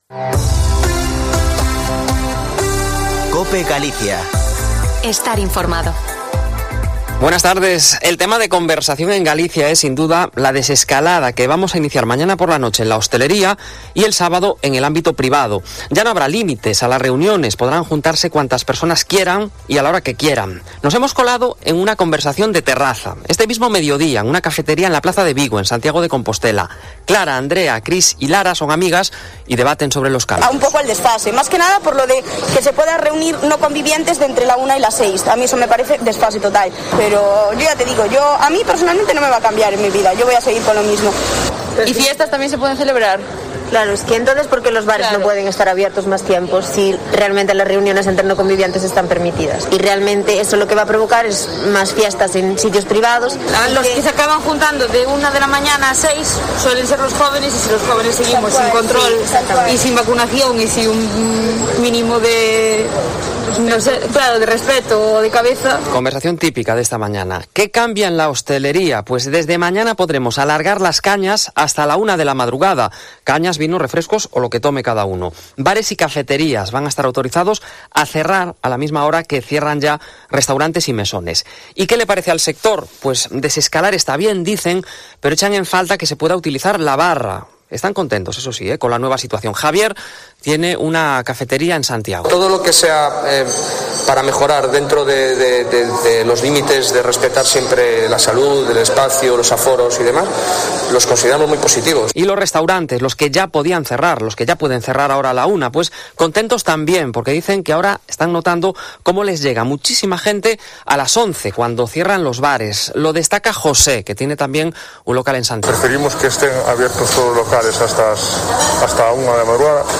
Informativo Mediodia en Cope Galicia 10/06/2021. De 14.48 a 14.58h